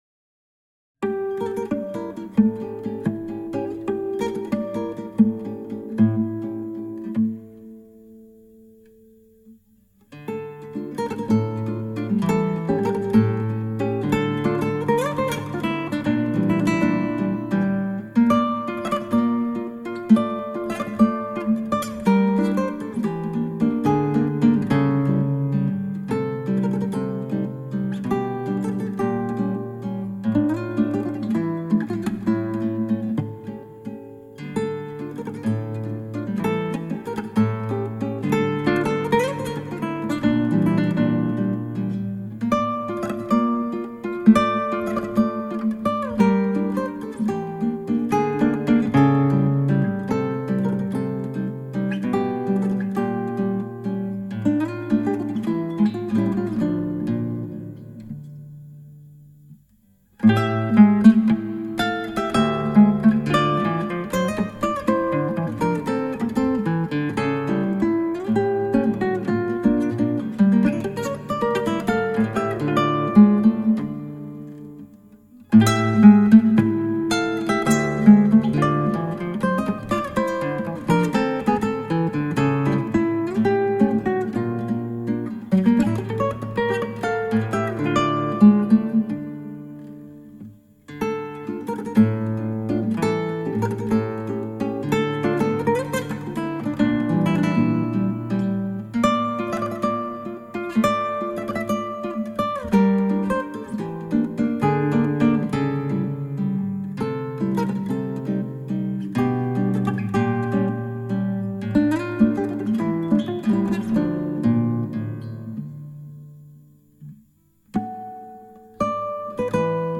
クラシックギター 「ターリラリラー・・・」 - 「ワルツ３番」 バリオス
ギターの自演をストリーミングで提供